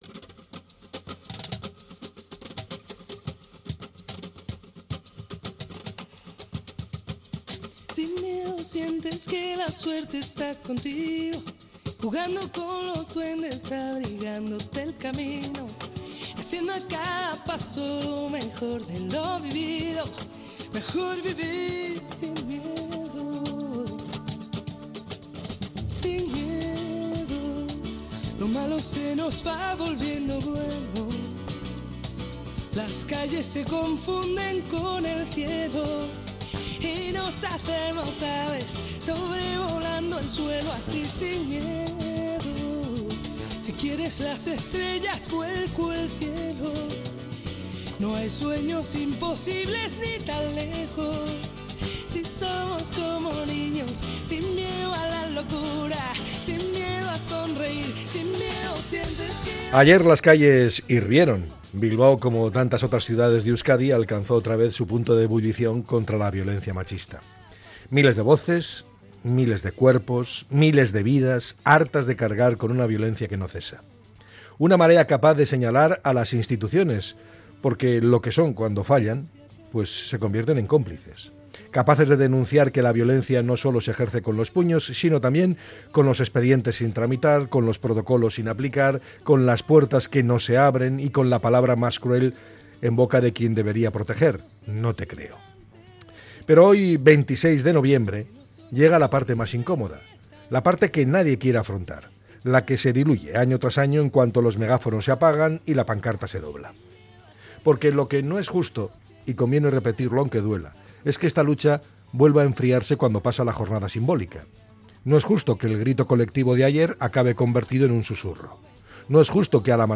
El comentario